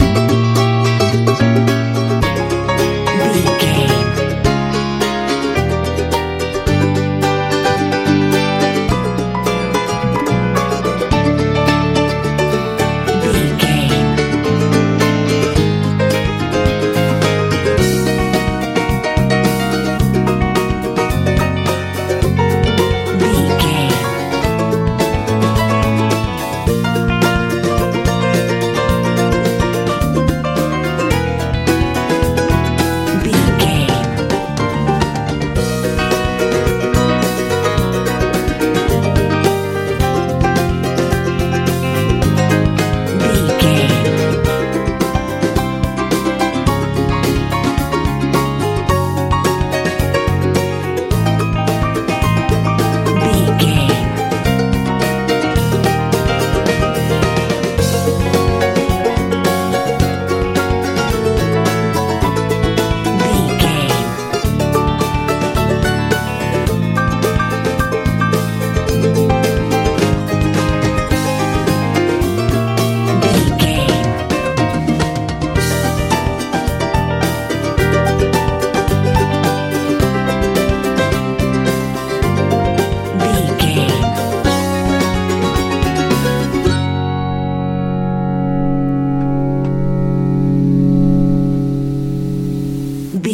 folk pop feel
Aeolian/Minor
bright
piano
acoustic guitar
bass guitar
drums
happy